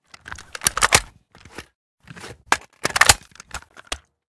svu_reload.ogg